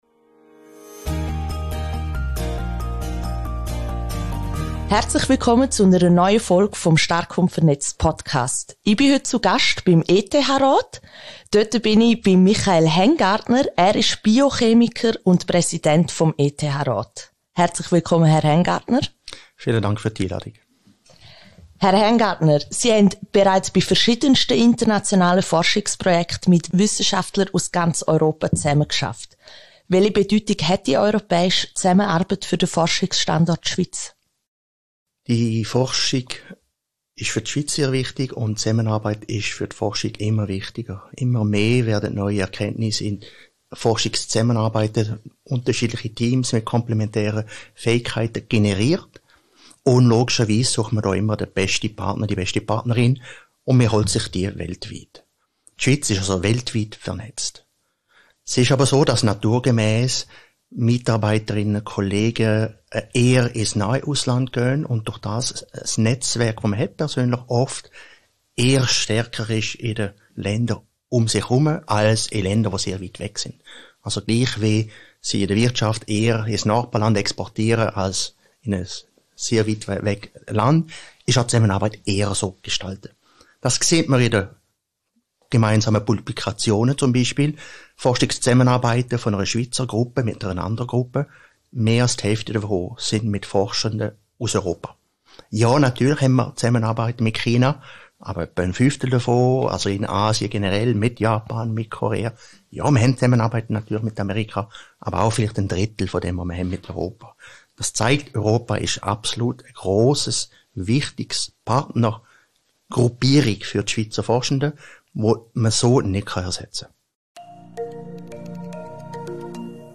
Gespräch mit Michael Hengartner, Präsident ETH-Rat